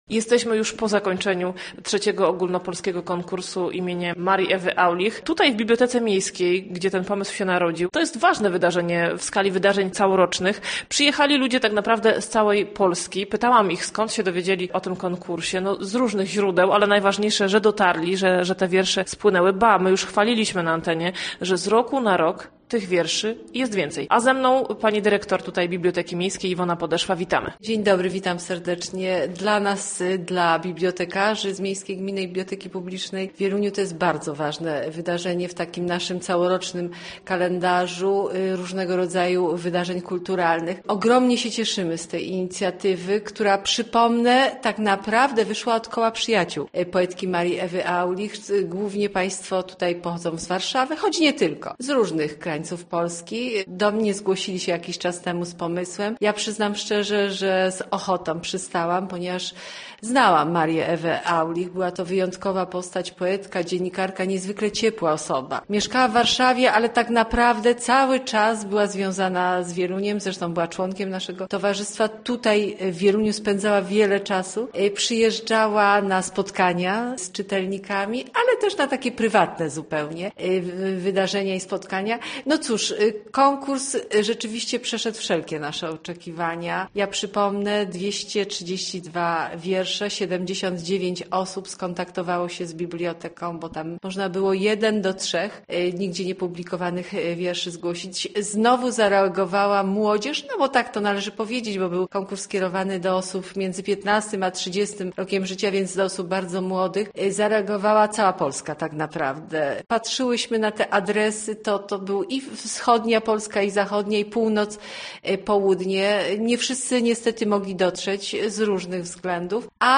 Zainteresowanie konkursem z roku na rok jest coraz większe. Tym razem 79 osób w wieku od 15 do 30 lat nadesłało 232 wiersze. Więcej na ten temat w rozmowie.